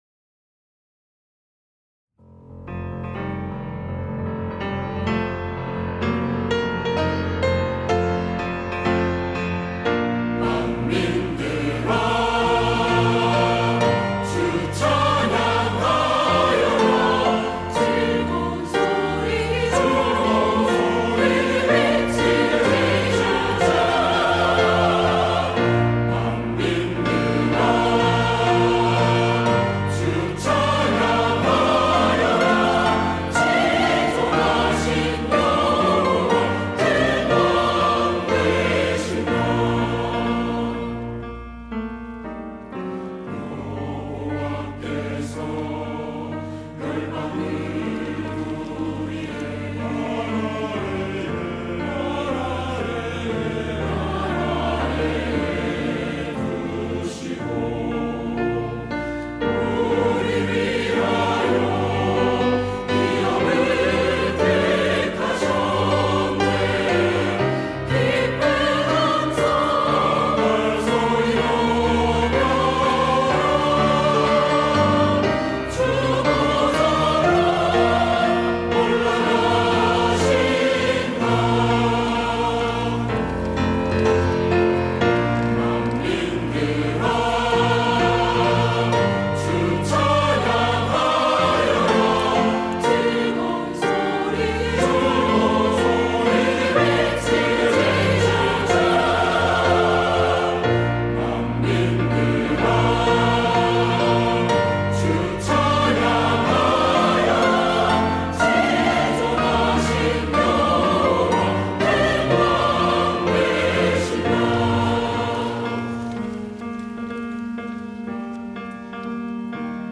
시온(주일1부) - 만민들아 주 찬양하여라
찬양대